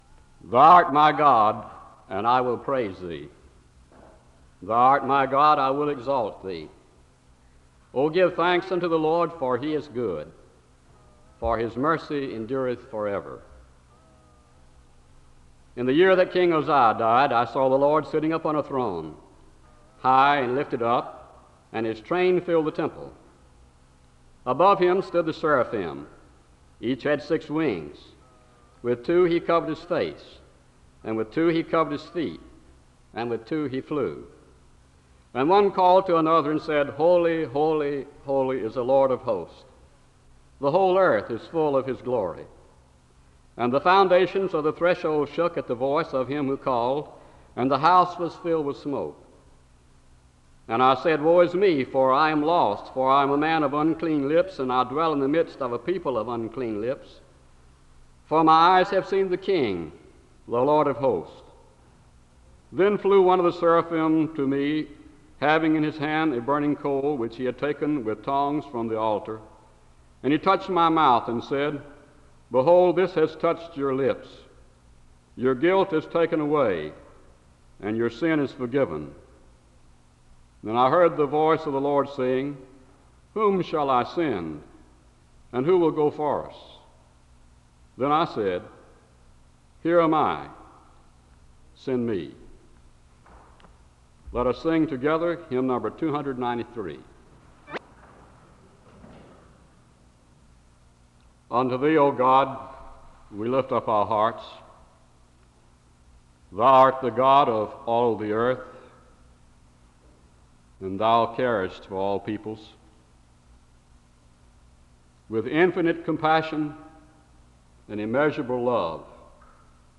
The service opens with a reading from Isaiah 6:1-8 (00:00-01:38).